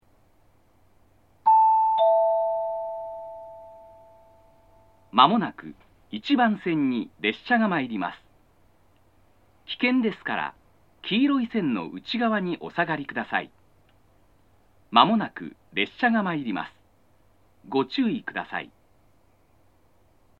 （男性）
接近放送
上り列車の接近放送です。放送更新により、上り下りで放送の男女が入れ替わっています。